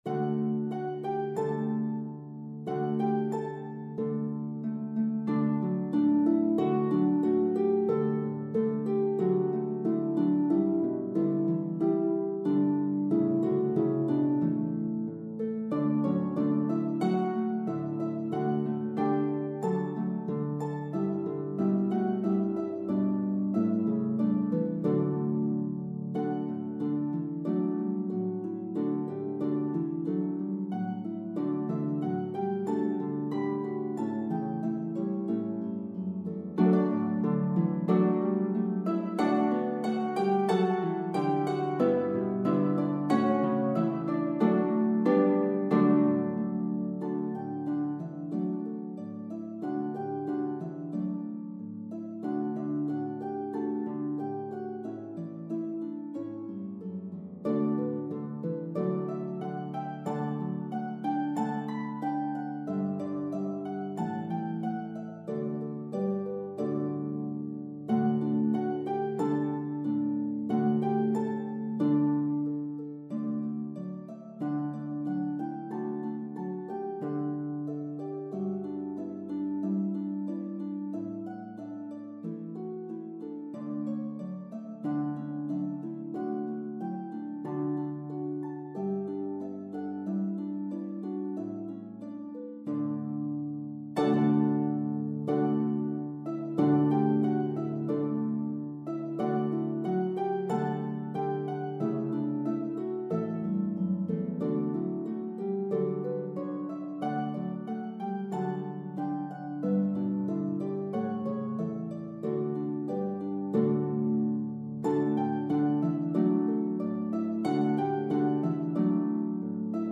Harp I – 6 pages
Harp II – 6 pages
Shaker “quick dance”